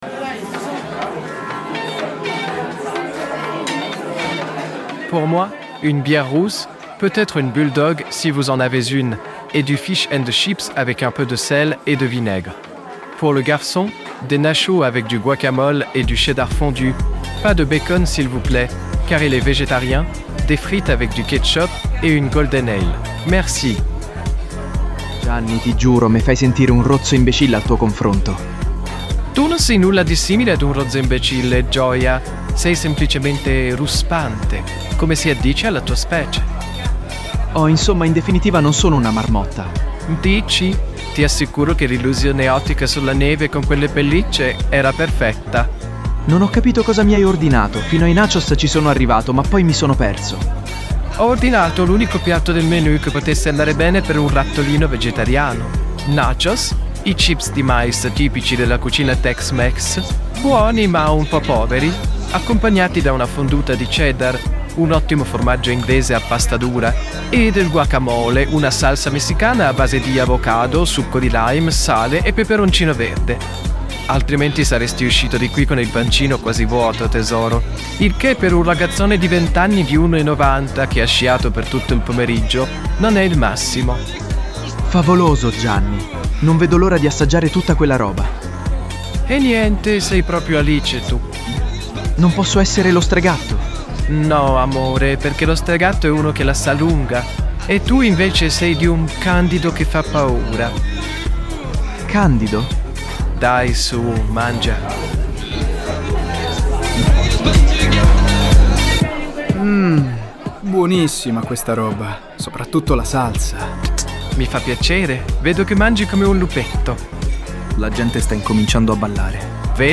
During the episode you can listen to songs and covers from "Music Sounds Better With You" (Stardust), "We Can Build A Fire" (Autoheart) and "Back" (Bad Boys Blue).